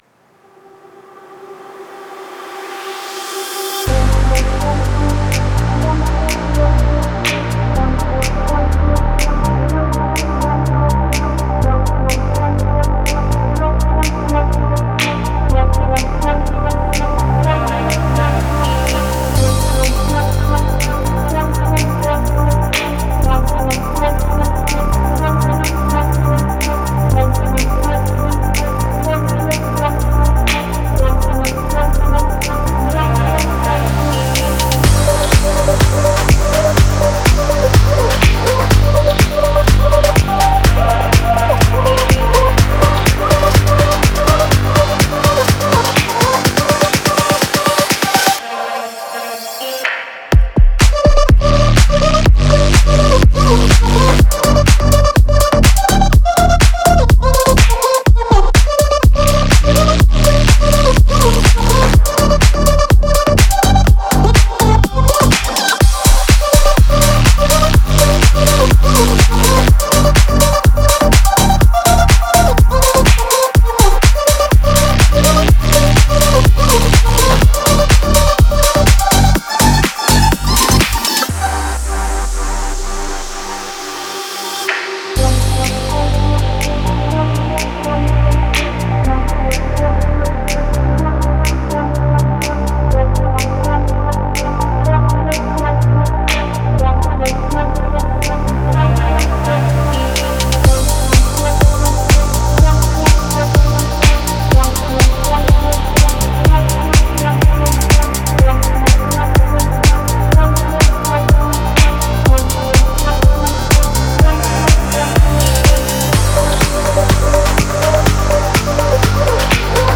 это динамичная электронная композиция в жанре EDM